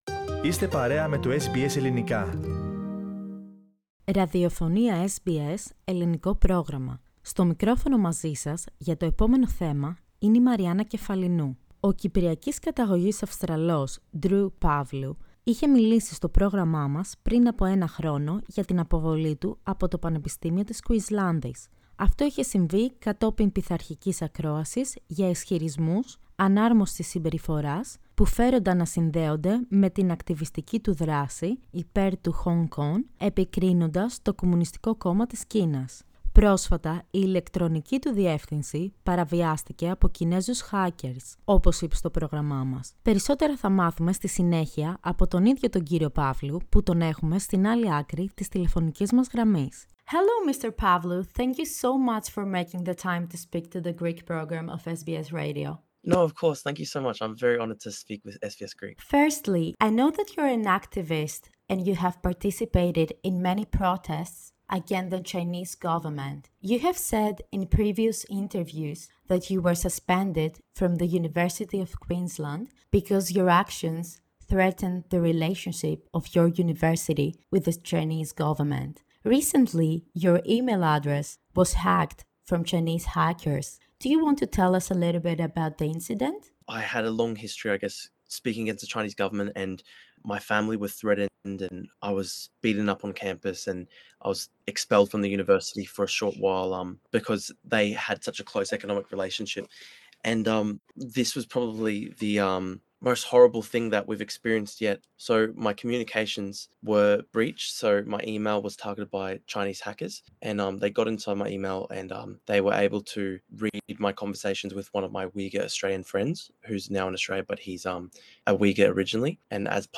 speaks to SBS Greek